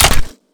weap_med_gndrop_4.wav